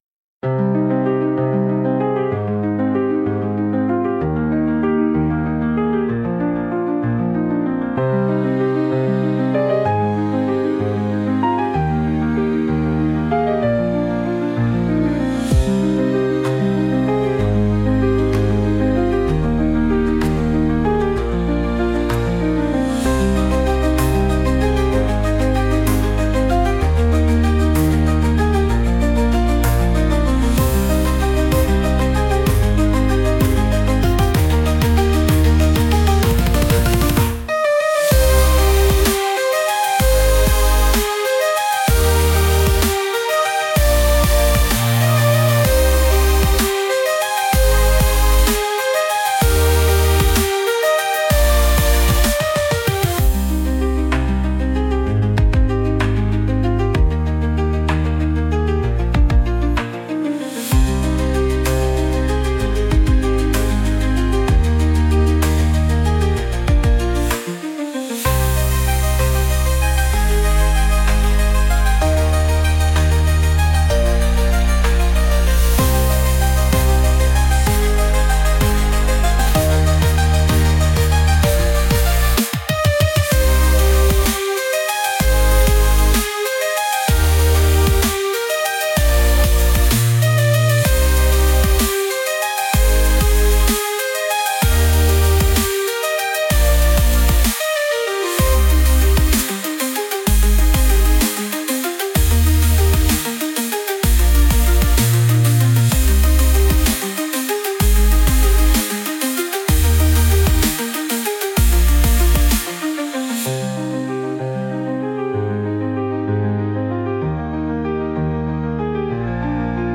Instrumental / 歌なし
最初は柔らかく繊細なピアノの音色で、迷いや不安を表現。
そこから曲が進むにつれて、だんだんと力強く、逞しい音へと変化していく構成が最大の特徴です。